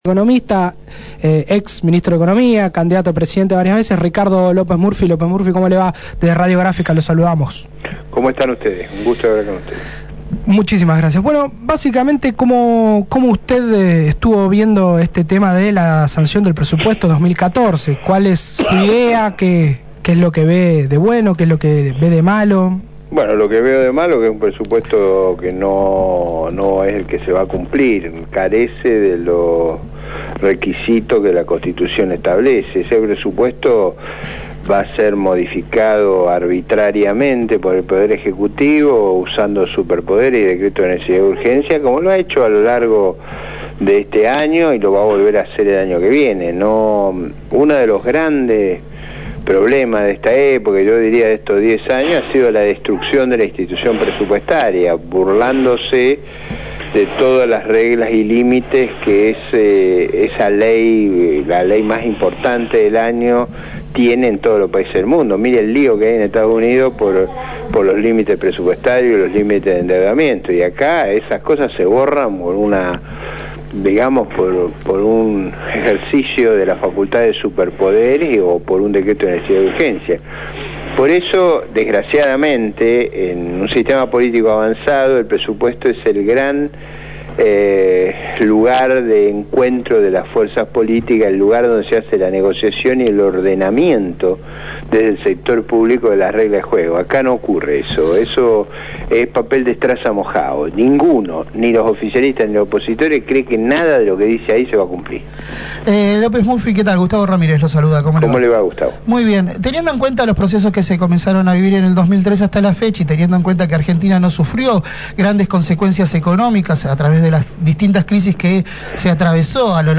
lmEn comunicación con Feos, Sucios y Malas, el ex ministro de economía durante la administración de la Alianza, criticó el presupuesto recientemente aprobado y comparó su plan económico con la política actual.